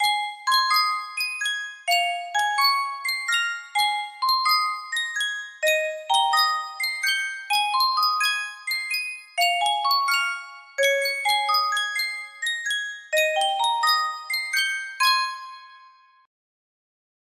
Yunsheng Musikkboks - Møykjaeringvise 5482 music box melody
Full range 60